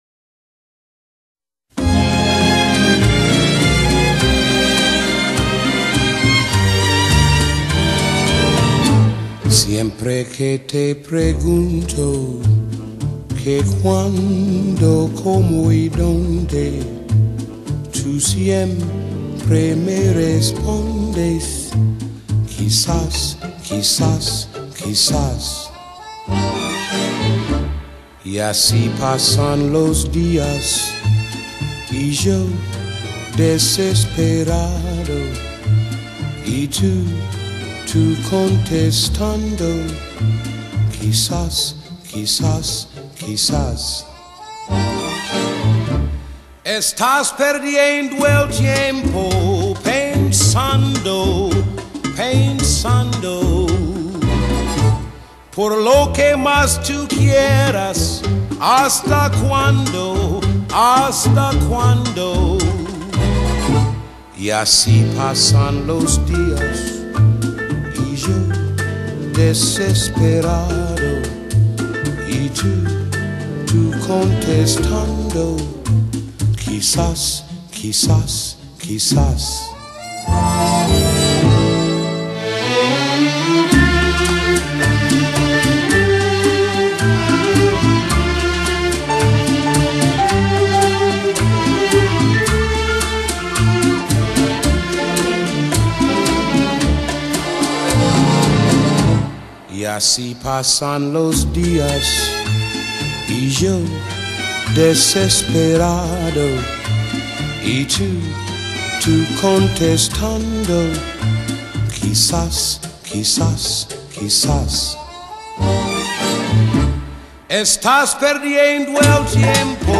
Latin, boleros, ballads
Sweet nostalgia in lossless.